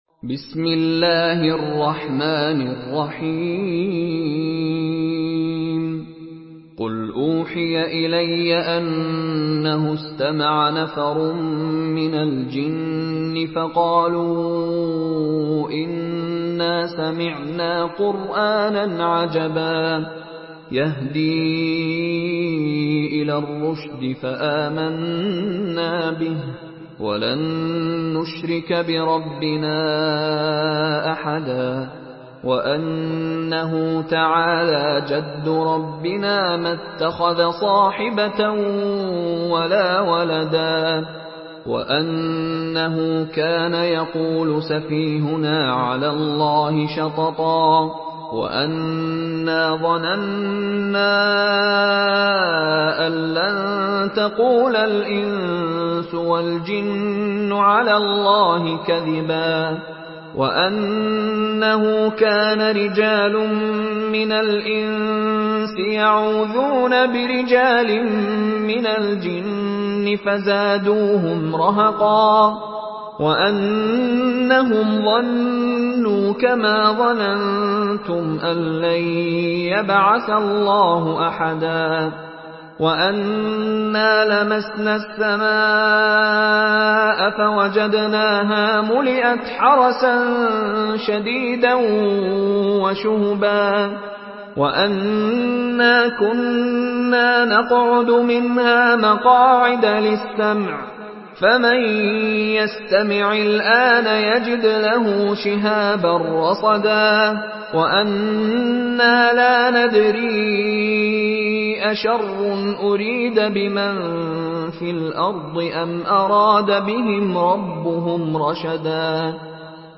Surah Al-Jinn MP3 in the Voice of Mishary Rashid Alafasy in Hafs Narration
Murattal Hafs An Asim